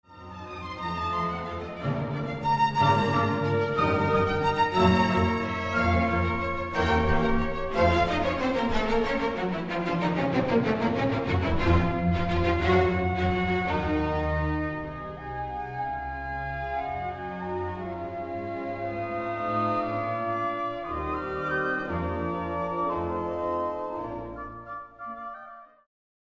It follows the outlines of sonata form but the journey is animated by some unusual harmonic twists and turns (including Db major in the transition and Gb major in the codetta) as well as frequent syncopations that develop on Beethoven’s style and perhaps anticipate the rhythmic style of Brahms.
Example 2 – Modulation in transition: